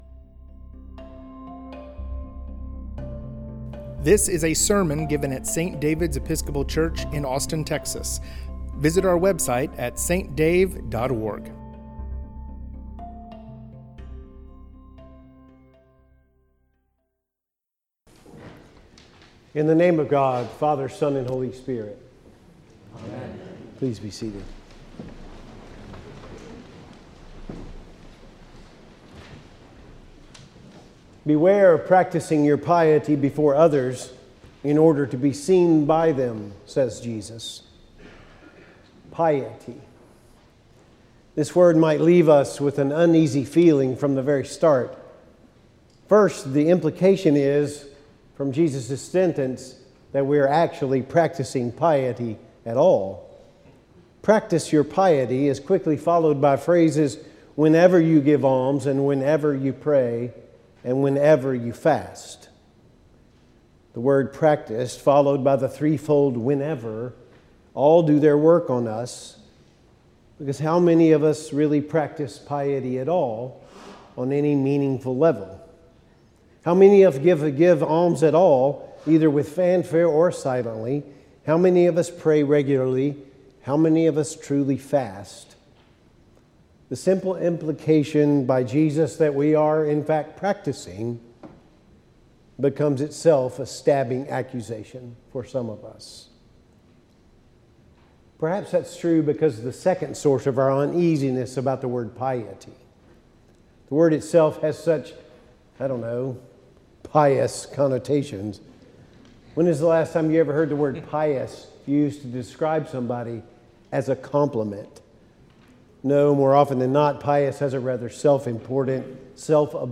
The Abbey at St. David's